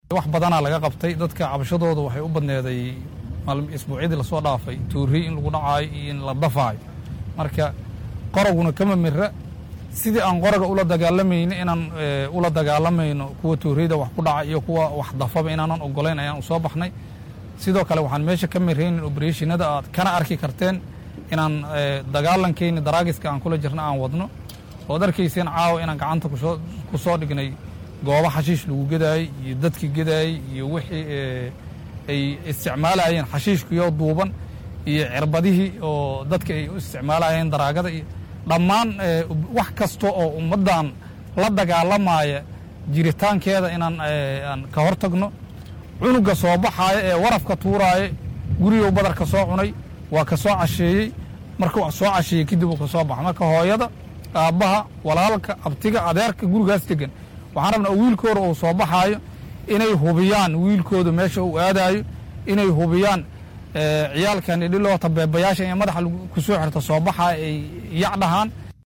Guddoomiyaha degmada Yaaqshiid Axmad Cabdullaahi Cariif oo ku hadlayay Magaca guddoomiyayaasha ayaa dhankiisa ugu baaqay Waalidiinta Ciyaal weerada ah iyo kuwa dhaca u geysta Shacabka inay ceshadaan haddii kale tallaabo laga qaadi doono.